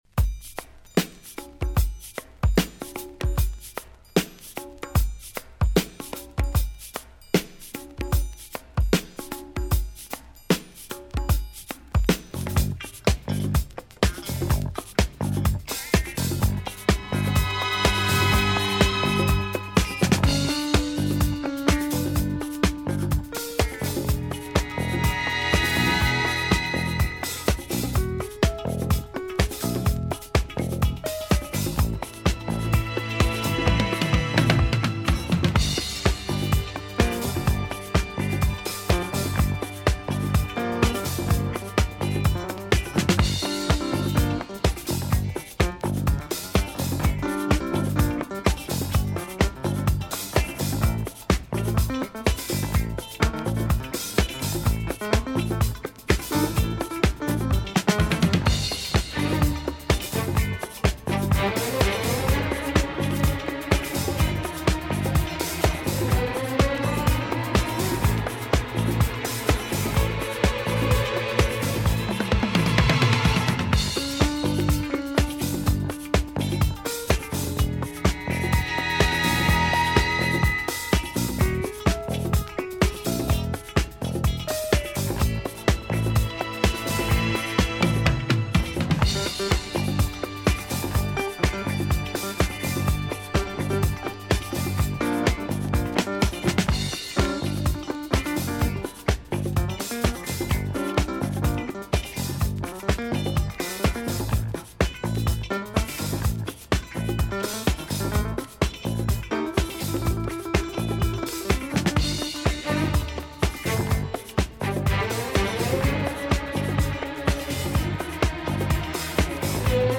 One uptempo funky discoid tune
(good beat & clavinet)
a good drum break intro